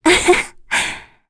Laias-Vox-Laugh1.wav